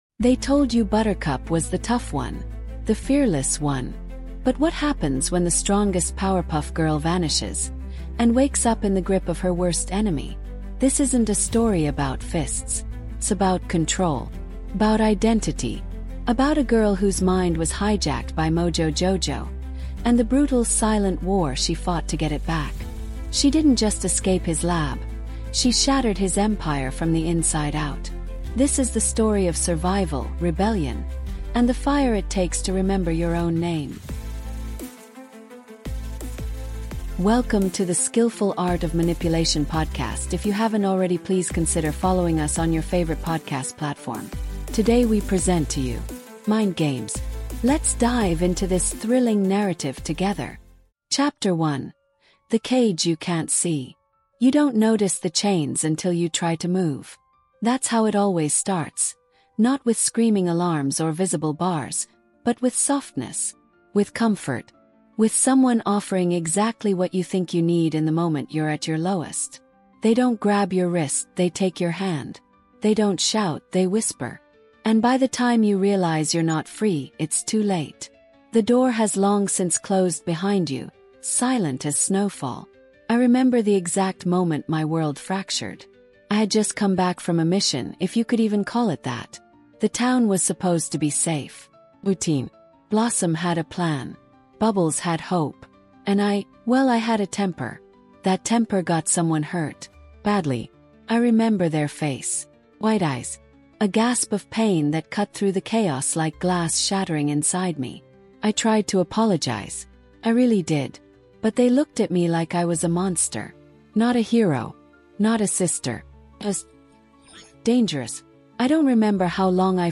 Mind Games | Audiobook